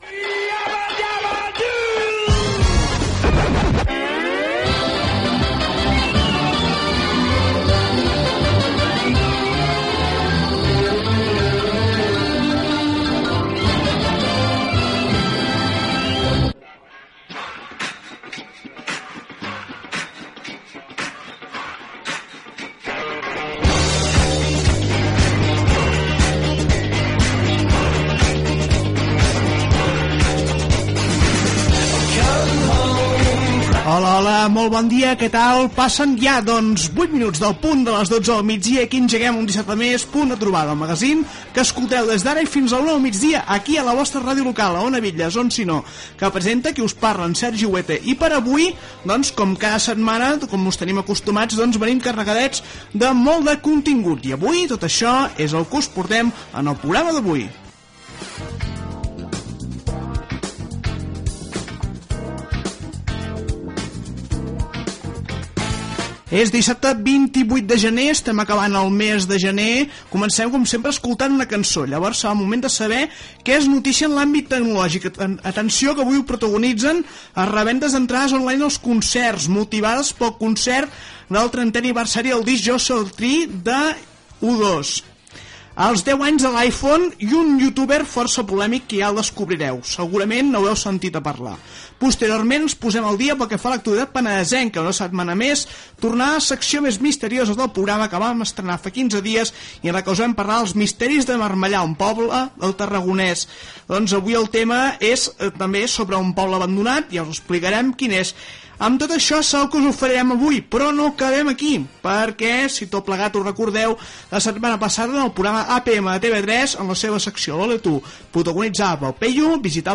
Sintonia, presentació i sumari del programa.
Entreteniment